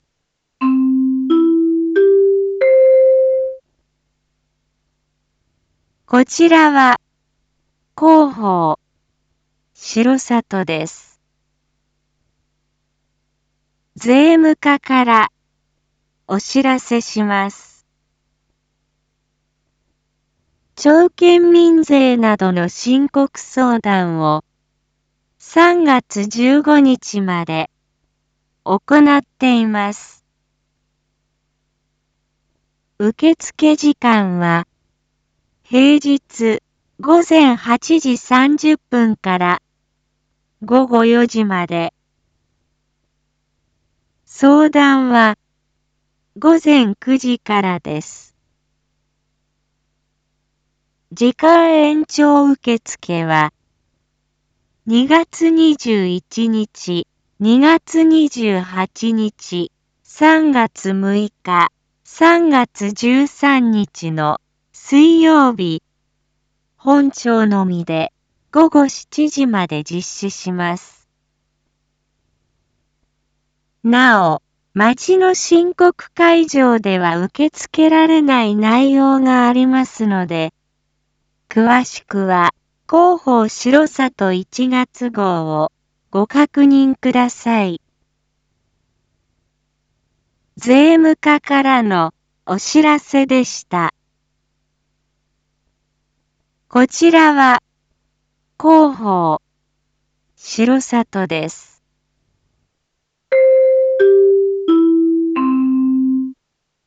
一般放送情報
Back Home 一般放送情報 音声放送 再生 一般放送情報 登録日時：2024-02-16 07:01:48 タイトル：申告相談A インフォメーション：こちらは広報しろさとです。